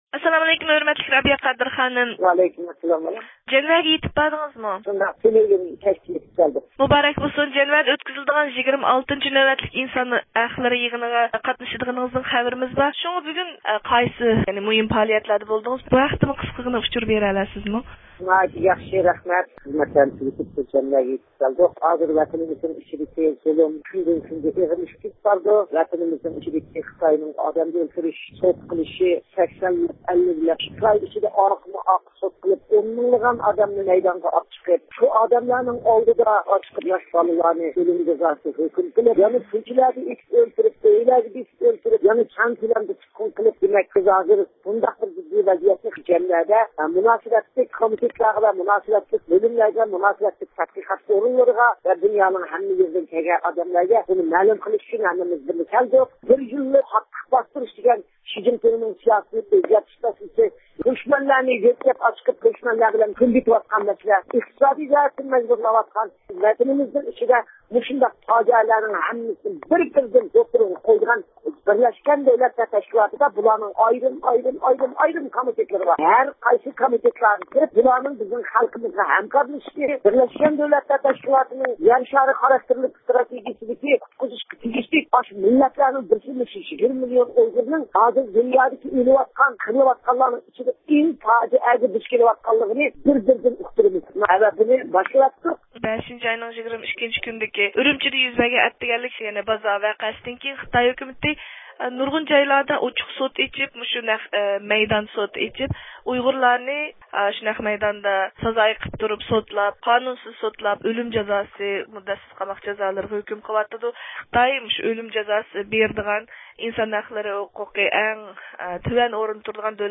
د ئۇ ق رەئىسى رابىيە قادىر خانىم تېلېفون زىيارىتىمىزنى قوبۇل قىلىپ، ئۇيغۇرلار نۆۋەتتە دۇچار بولۇۋاتقان ئېغىر ۋەزىيەتنى ۋە قىيىن مەسىلىلەرنى بىرلەشكەن دۆلەتلەر تەشكىلاتى ئىنسان ھەقلىرى كومىتېتى ۋە ھەر قايسى دۆلەتلەرگە ئاڭلىتىش توغرىسىدىكى پىلان ۋە پائالىيەتلەر ھەققىدە مەلۇمات بەردى.